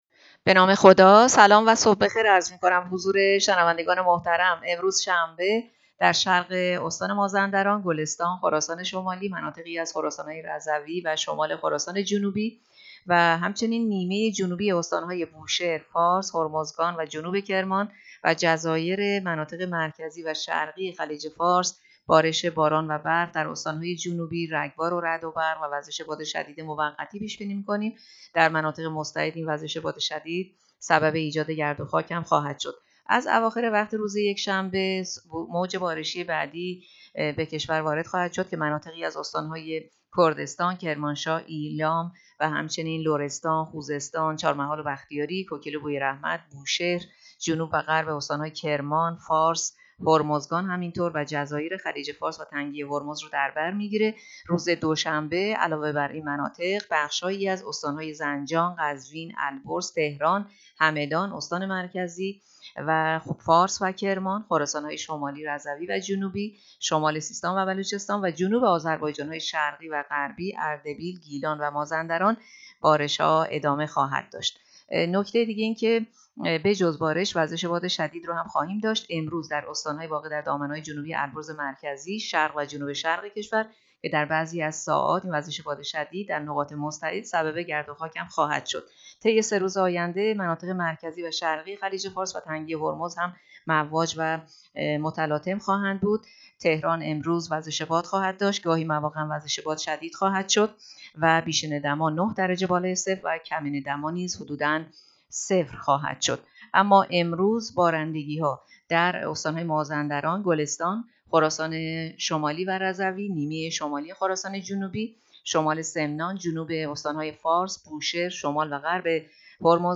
گزارش رادیو اینترنتی پایگاه‌ خبری از آخرین وضعیت آب‌وهوای ۲۷ بهمن؛